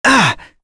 Fluss-Vox_Damage_01.wav